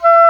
WND D OBOE04.wav